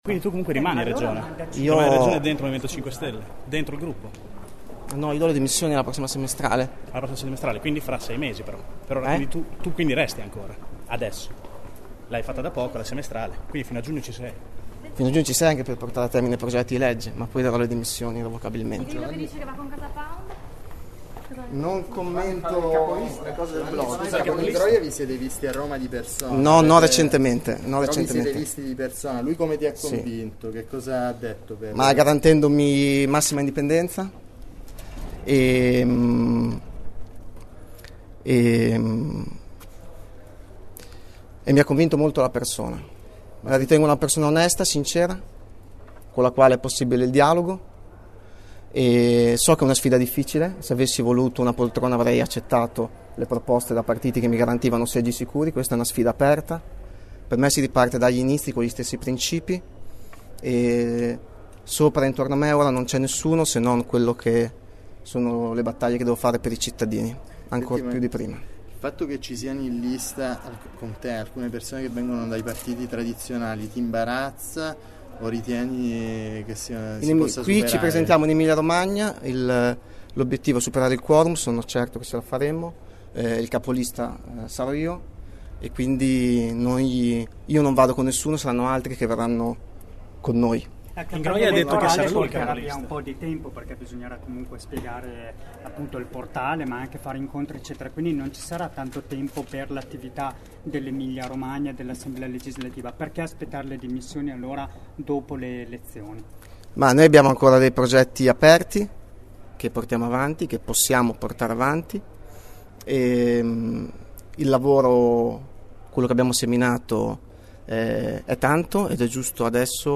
Al termine della lettura del comunicato, Favia ha cercato di guadagnare in fretta gli ascensori per risalire nel proprio ufficio. Non è riuscito però ad evitare le domande dei cronisti, e soprattutto quelle di alcuni attivisti dell’assemblea bolognese di Cambiare si può che l0 hanno apertamente contestato. “Non ti vergogni?” chiedevano alcuni, in tutto meno di una decina.